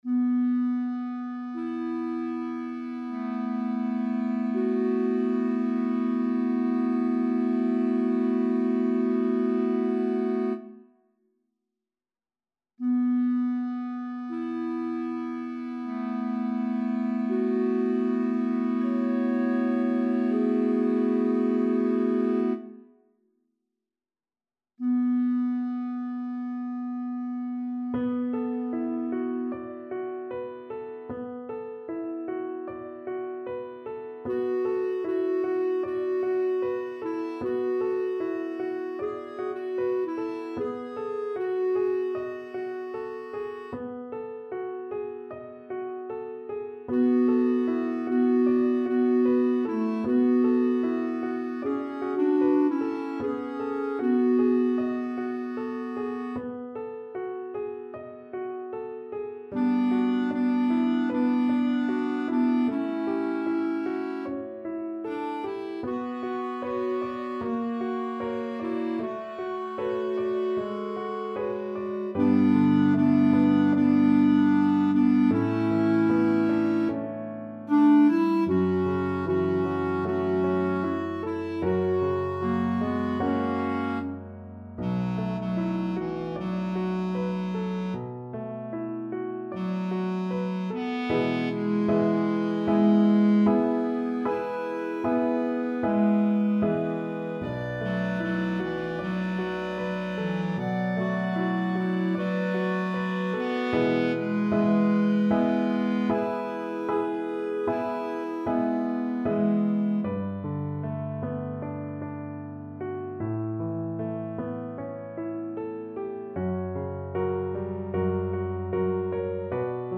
SATB mixed choir and piano
世俗音樂